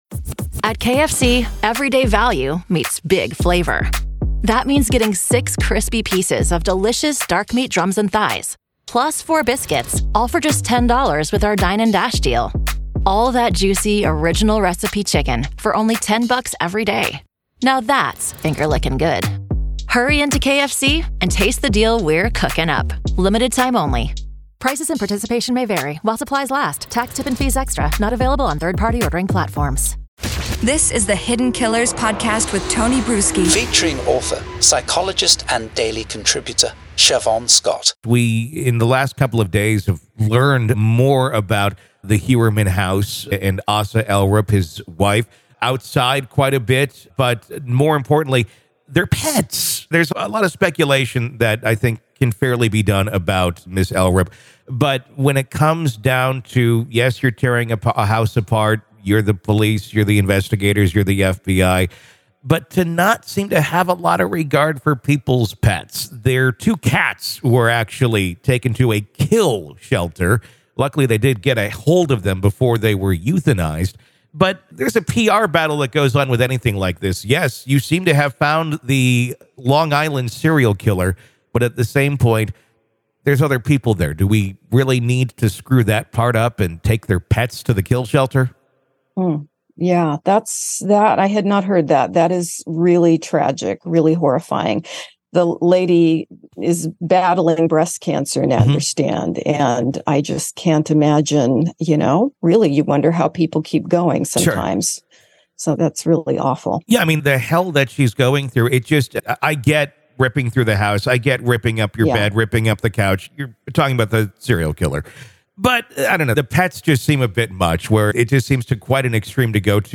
The duo touched on the challenges that arise when those expected to be protectors – in this case, law enforcement officials – become predators.